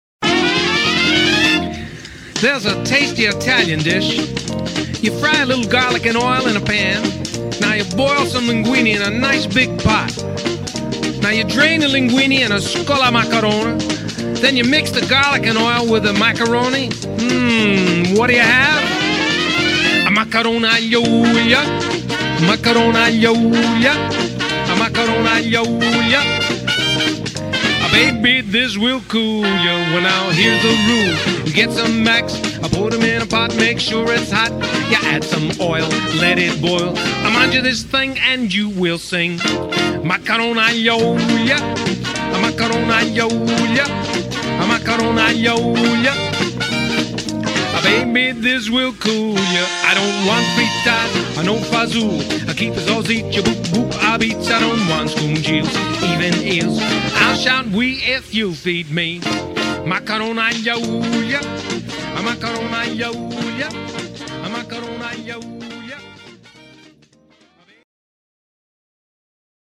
Vocal Band